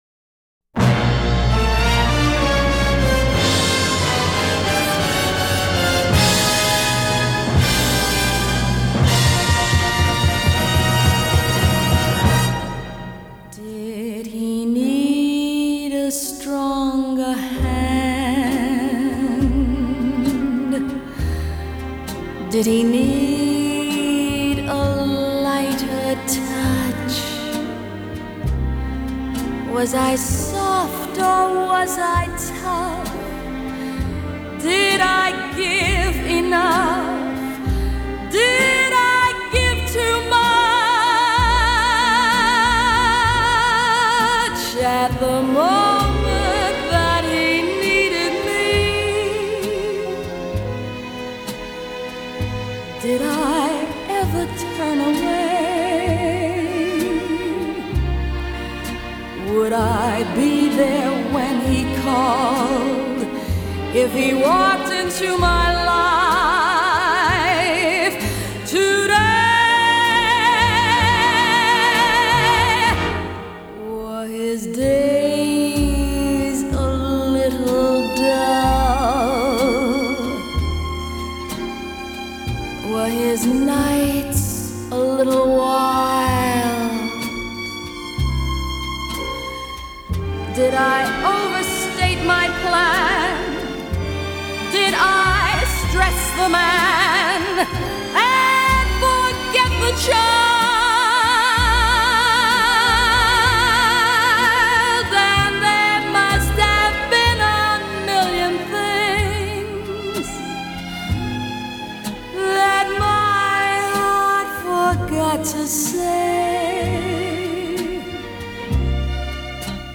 Here she is belting it out within an inch of its life.